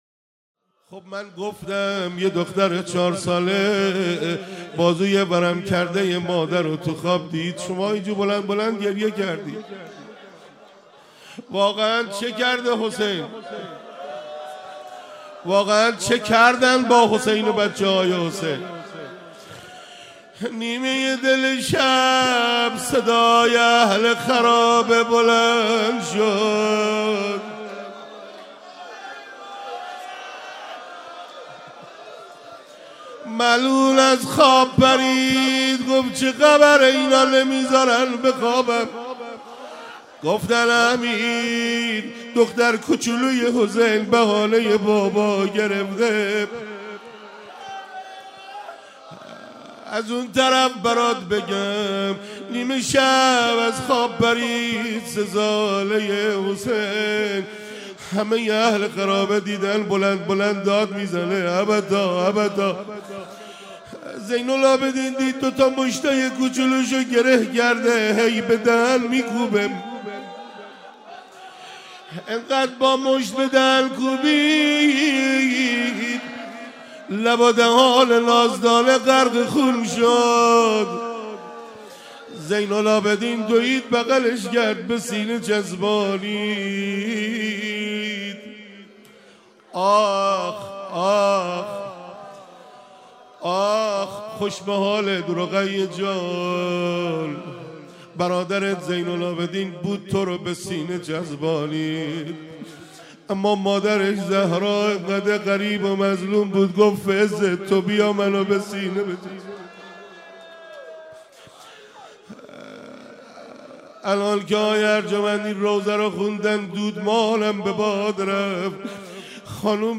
حسینیه انصارالحسین علیه السلام
15 بهمن 96 - انصار الحسین - روضه حضرت رقیه سلام الله علیها